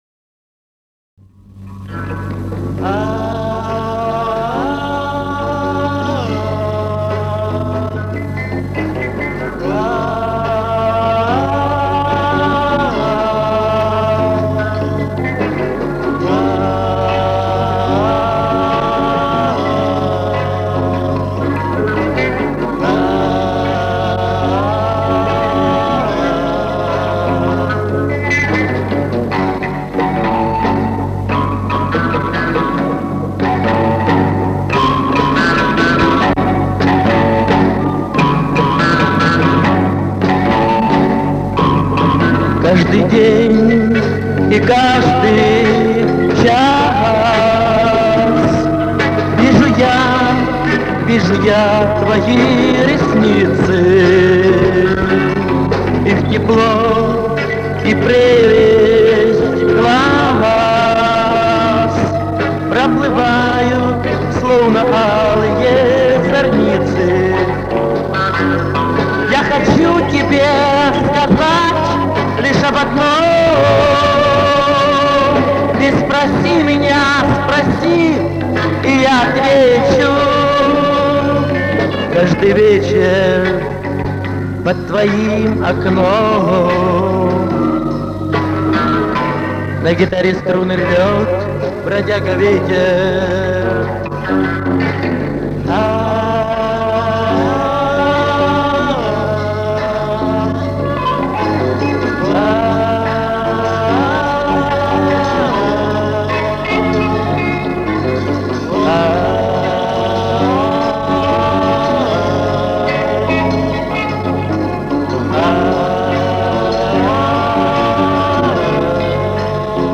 Хоть и мотив немного не тот.